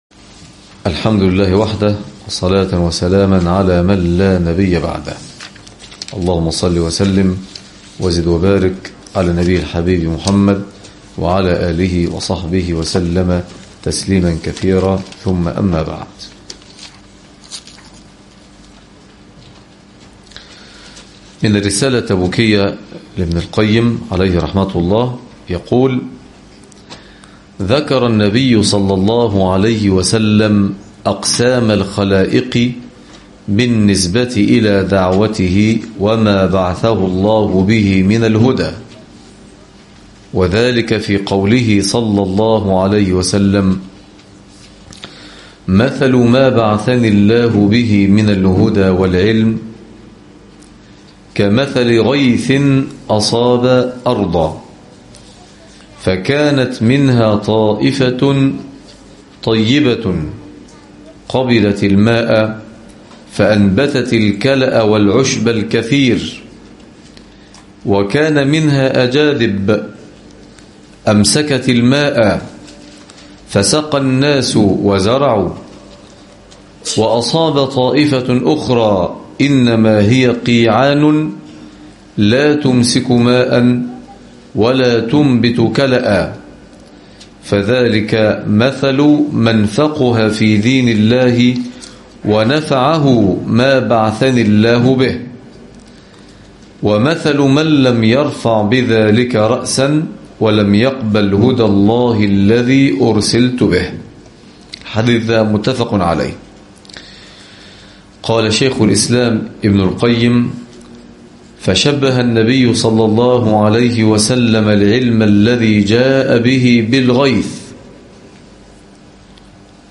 الدرس السابع عشر- تزكية- قراءة من الرسالة التبوكية لابن القيم